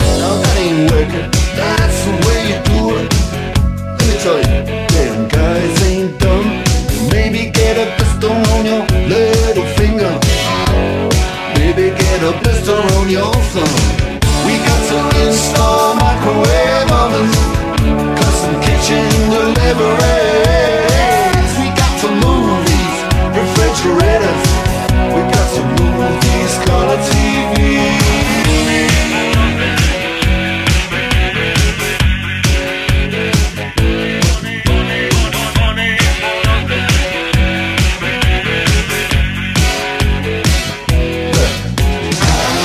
Genere: pop, rock, elettronica, successi, anni 80